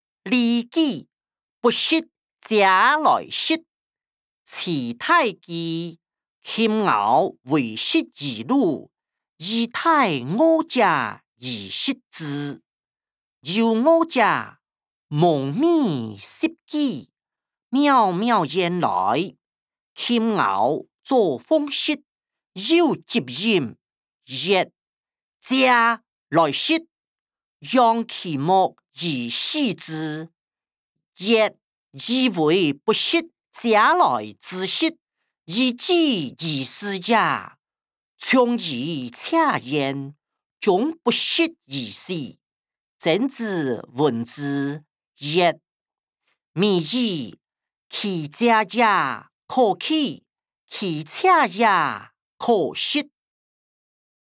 經學、論孟-不食嗟來之食音檔(大埔腔)